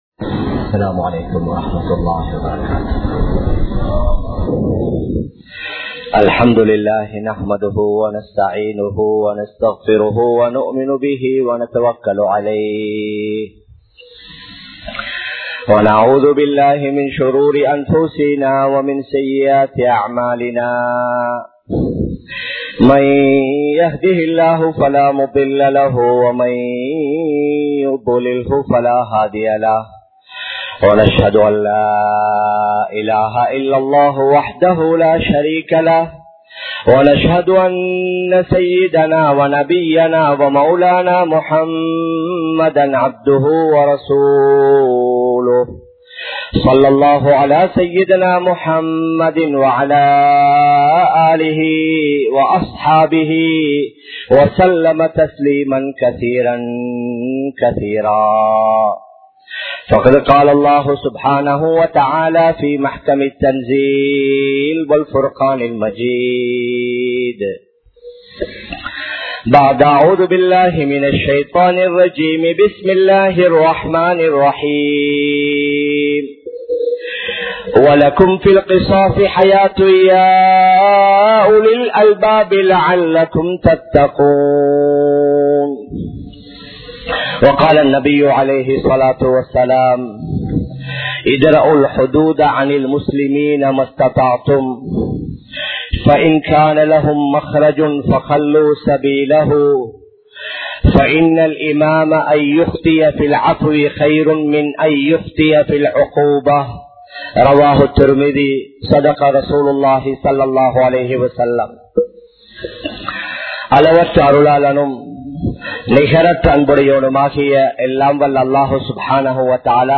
Seeraana Sharihah Sattankal (சீரான ஷரியாஹ் சட்டங்கள்) | Audio Bayans | All Ceylon Muslim Youth Community | Addalaichenai
Dehiwela, Muhideen (Markaz) Jumua Masjith